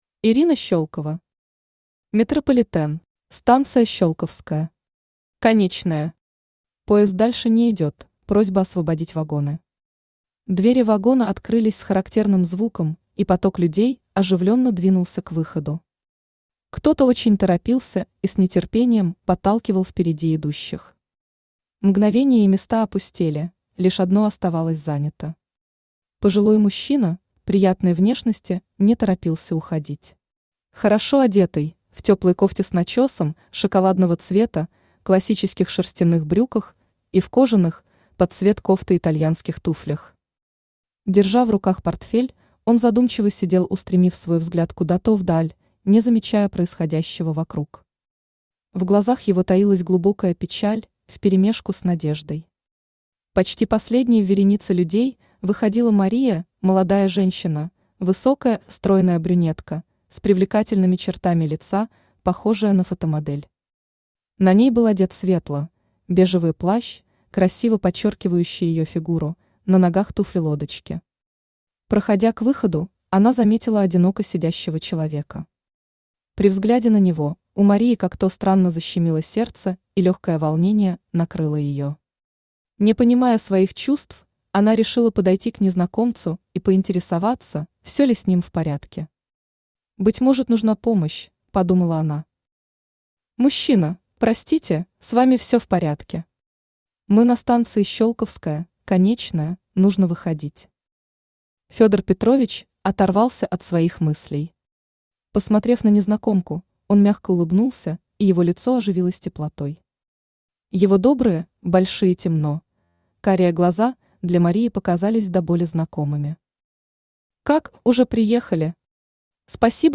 Читает аудиокнигу Авточтец ЛитРес.